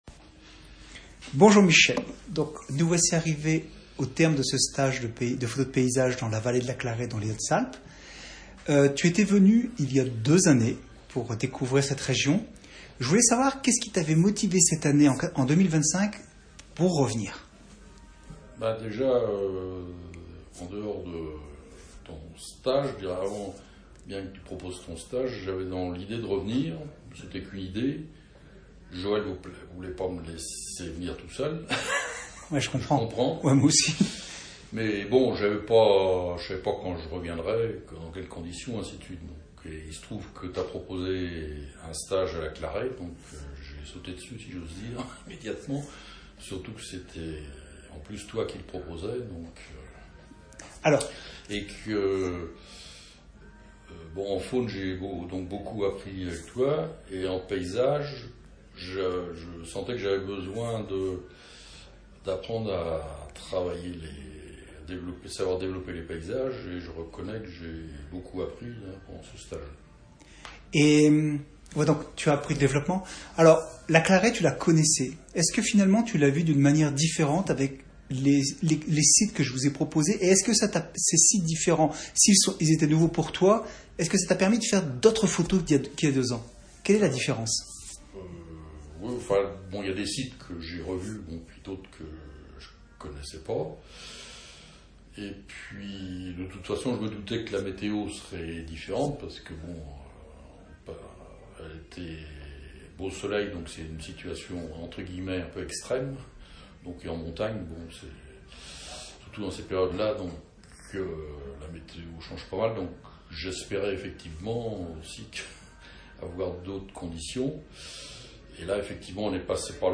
Le commentaire oral des participants